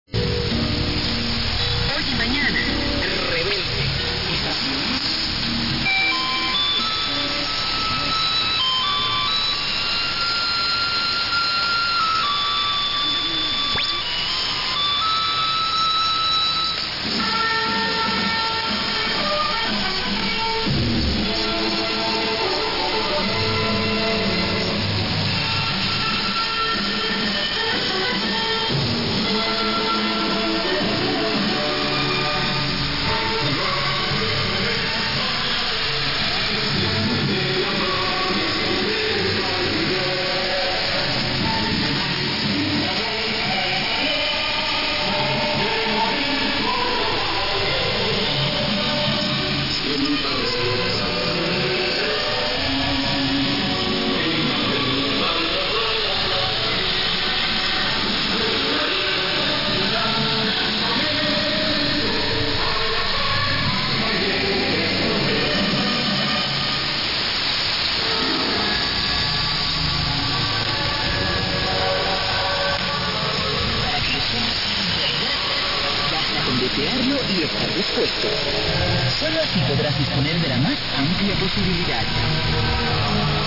Piccolo log di ascolti notturni, sono volontariamente state omesse tutte le stazioni spagnole in onde medie visto che si ricevono pure attaccando uno spezzone di filo agli orecchini 😉
Ricevitore SDR Play 1, antenna Maxiwhip, Grid: JN45rt